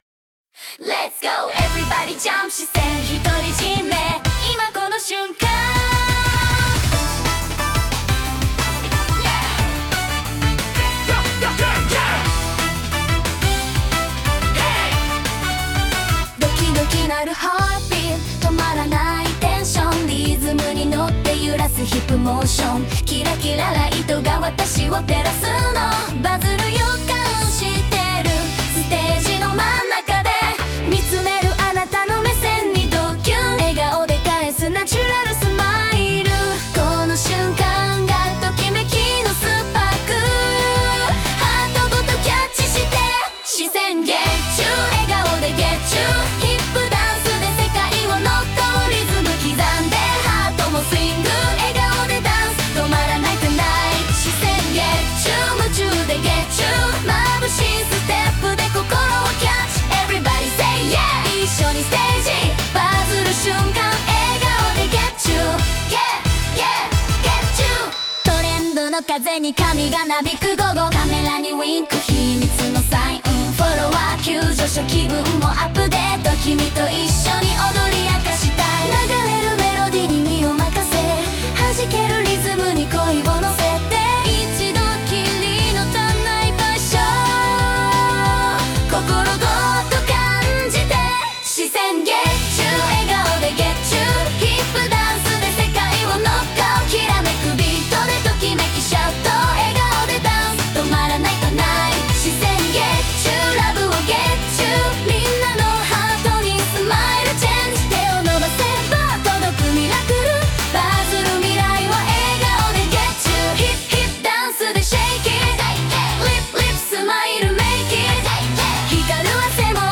明るい気持ちになれるハッピーソング！